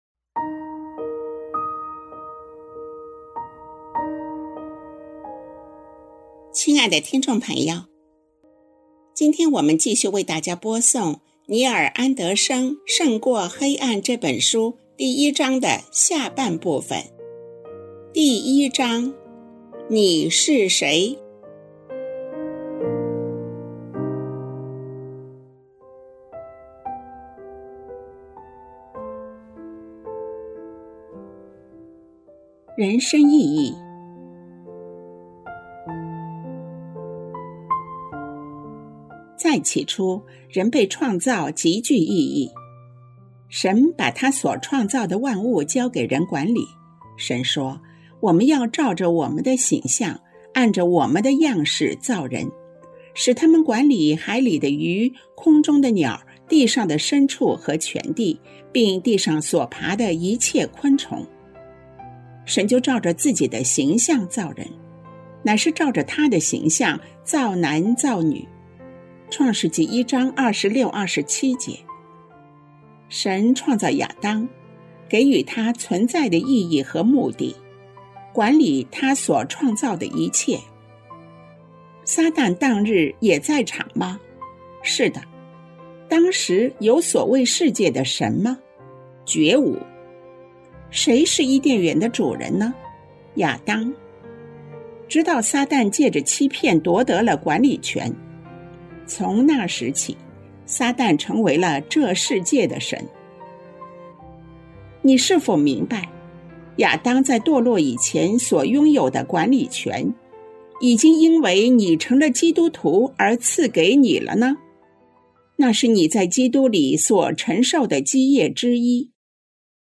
作者：尼尔·安德生 亲爱的听众朋友，今天我们为大家播诵 尼尔·安德生的《胜过黑暗》